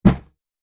Foley Sports / Boxing / Punching Box Intense A.wav
Punching Box Intense A.wav